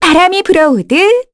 Cassandra-Vox_Skill4-2_kr.wav